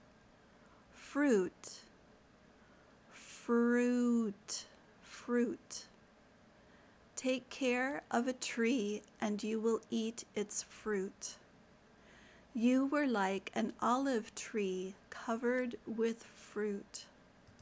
/fruːt/ (noun)